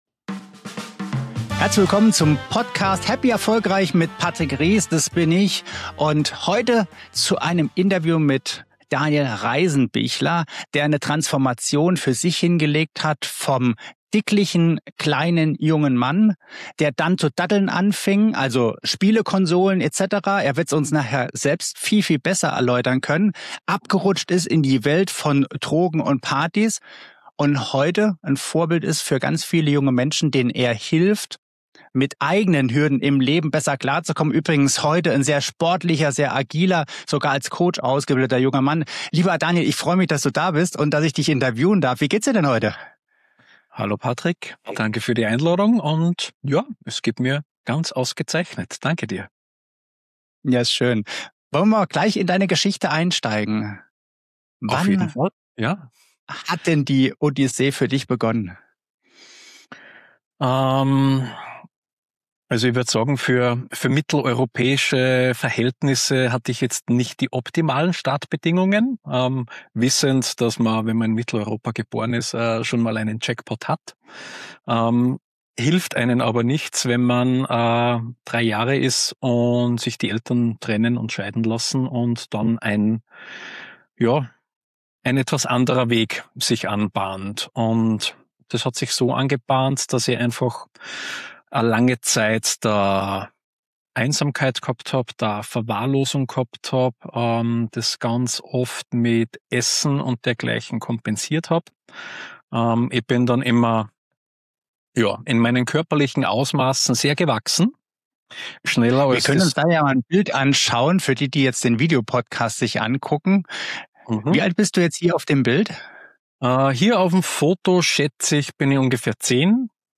Es ist ein Gespräch über Wendepunkte. Über Sport als Einstieg in die Veränderung. Über neue Umfelder, neue Entscheidungen und den Moment, in dem man beginnt, Verantwortung für das eigene Leben zu übernehmen.